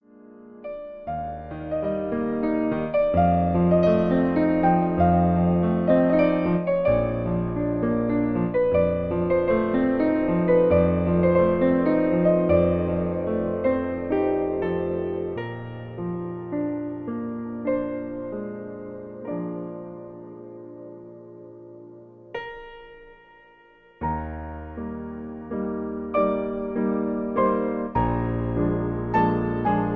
Berühmtes Duett aus der Operette